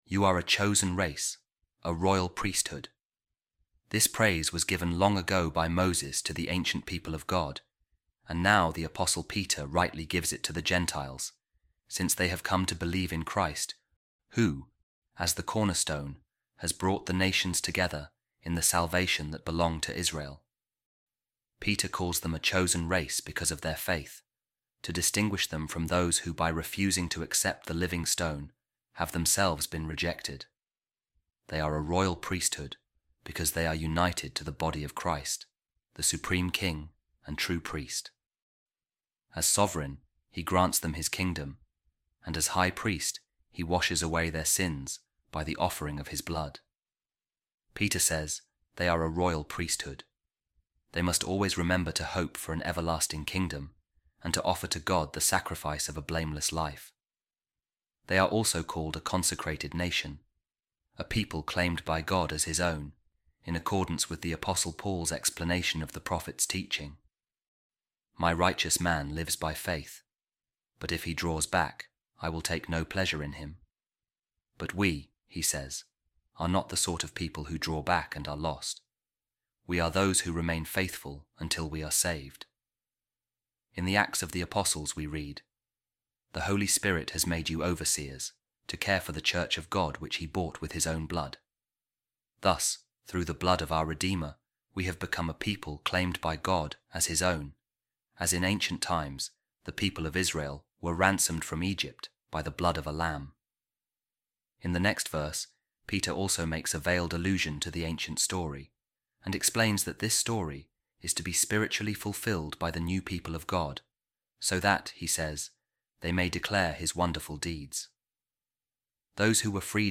Office Of Readings | Eastertide Week 3, Monday | A Reading From The Commentary Of Saint Bede The Venerable On The First Letter Of Saint Peter | A Chosen Race, A Royal Priesthood